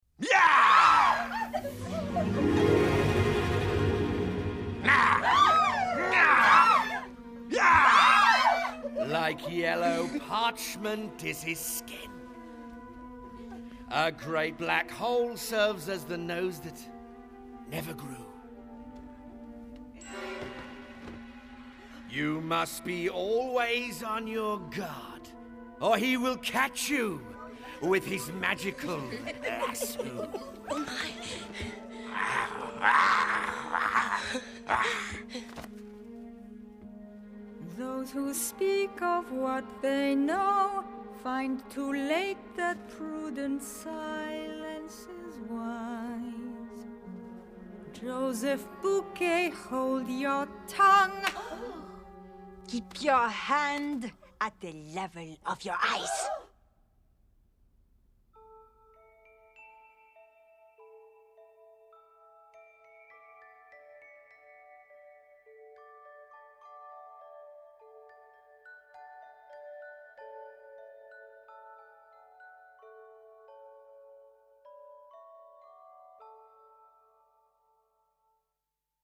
音乐类型：电影配乐